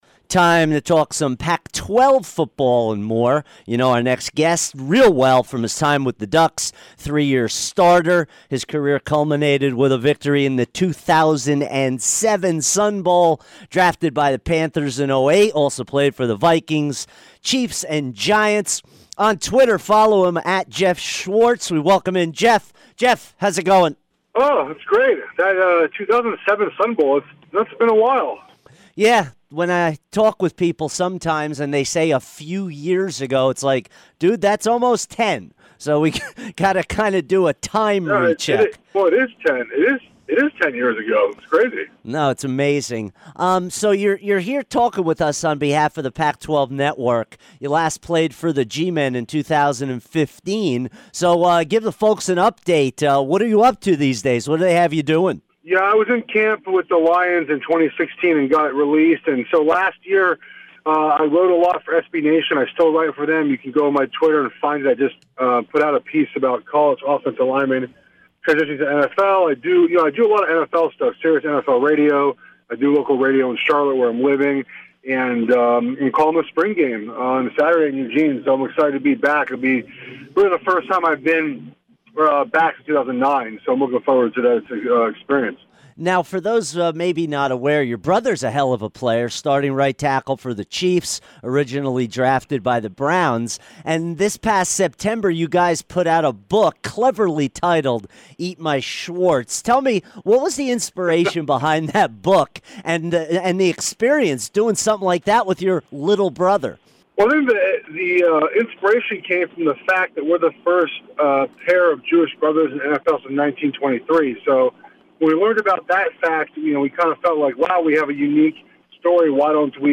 Geoff Schwartz Interview 4-27-17